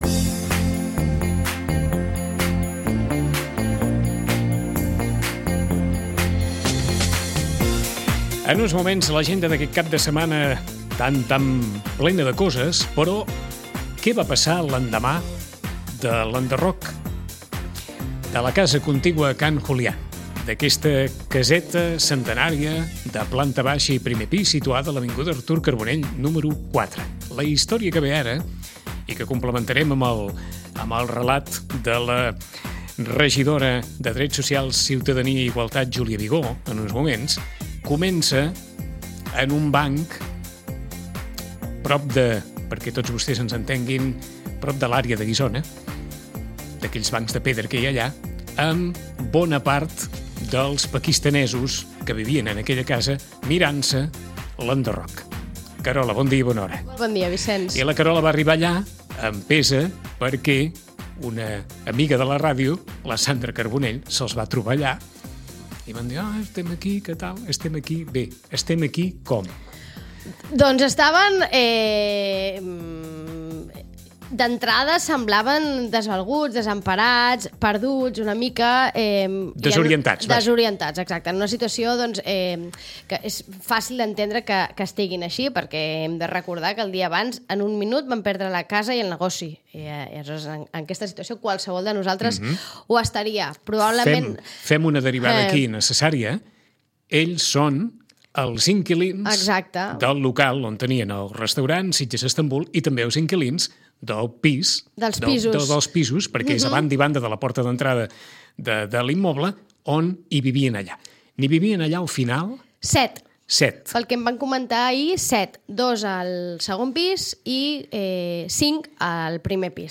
Hem conversat també amb la regidora de Serveis Socials, Júlia Vigó, que ha explicat que de moment podran allotjar-se al pis que té adequat la Creu Roja i que se’ls oferirà també assessorament jurídic.